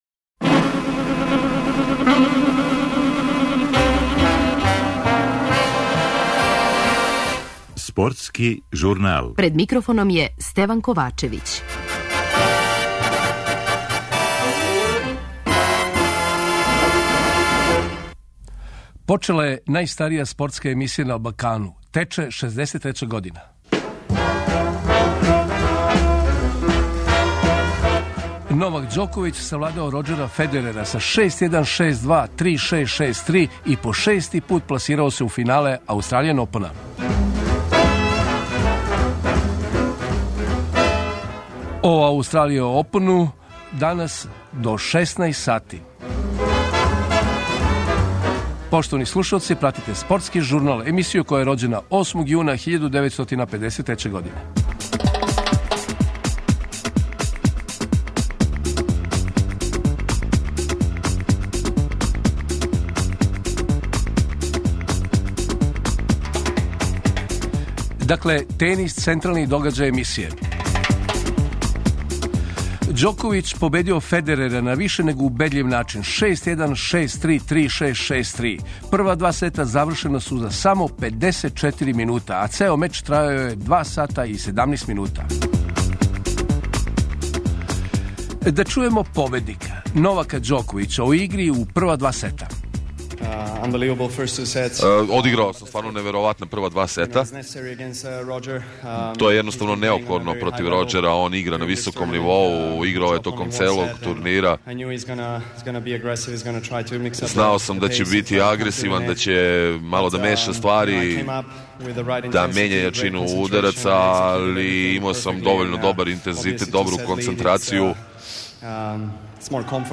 Гости емисије су најпознатији тениски стручњаци Србије
Они ће анализирати данашњу игру Ђоковића, али и најавити недељно финале.